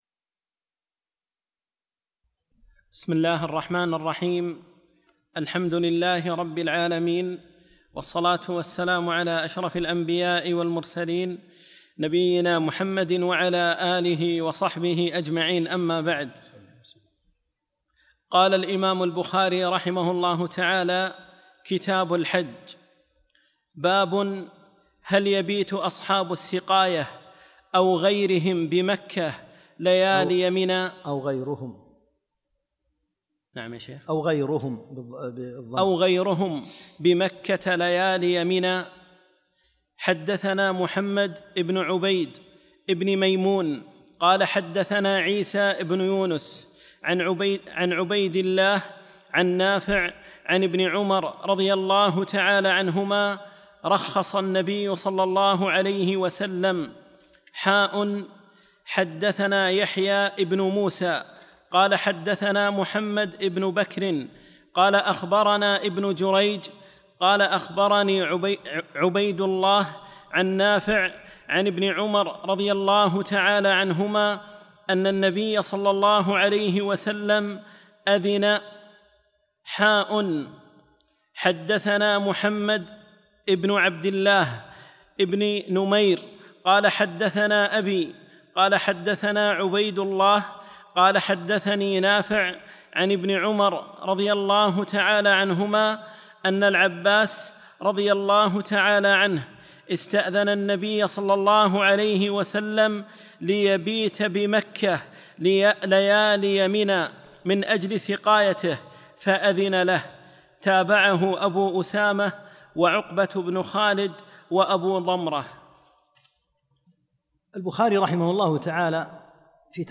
21 - الدرس الحادي والعشرين